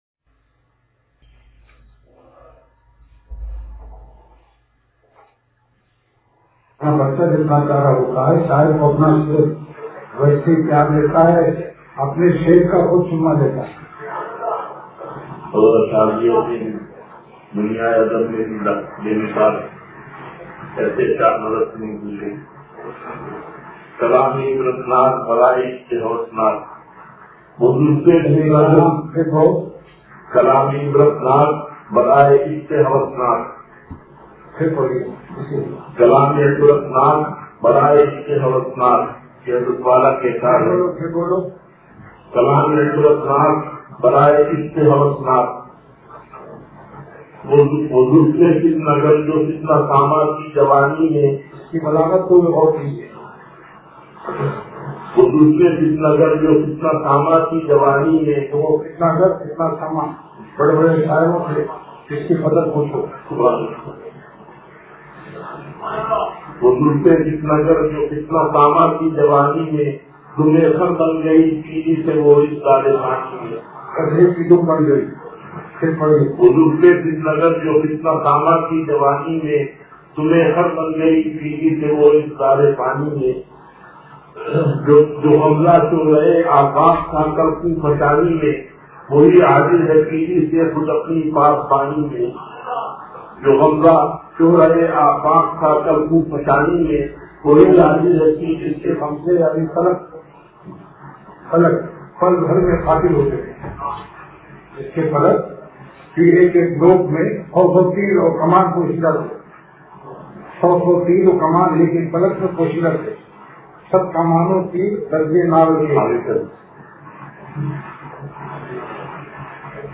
بیان – حضرت والا رحمت اللہ علیہ – اتوار